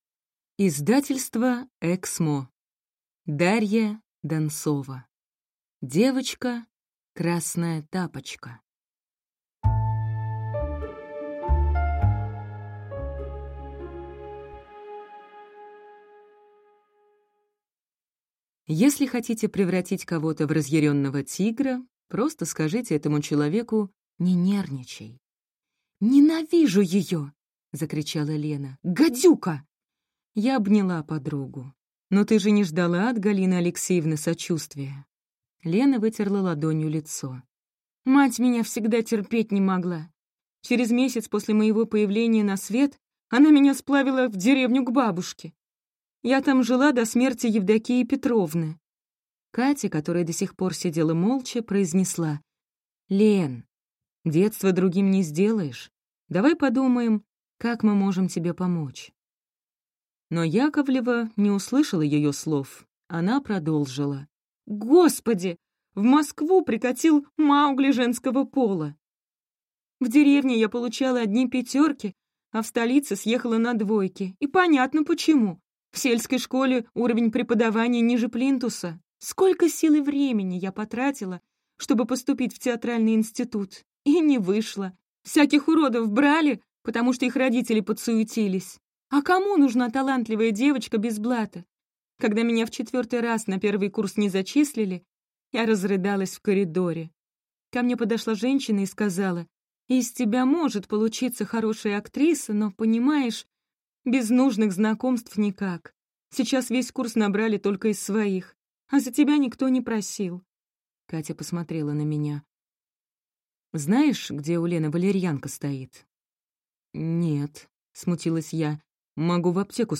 Аудиокнига Девочка Красная Тапочка | Библиотека аудиокниг